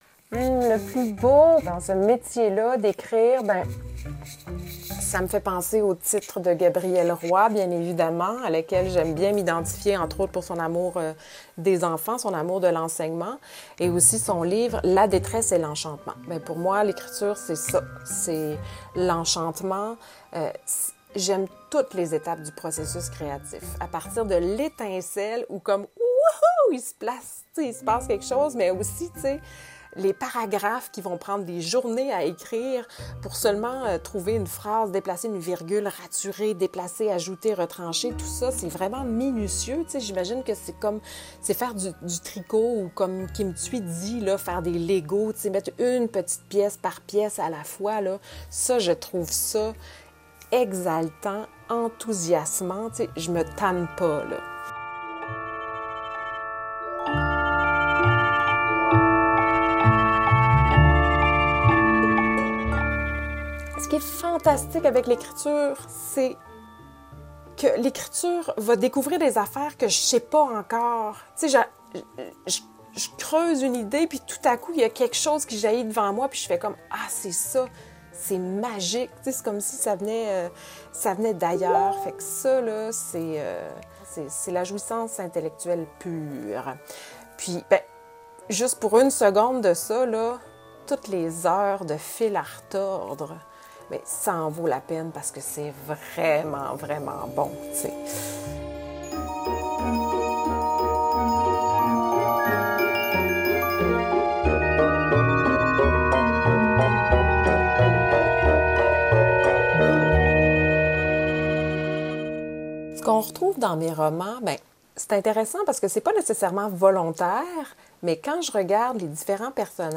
En conversation avec RCI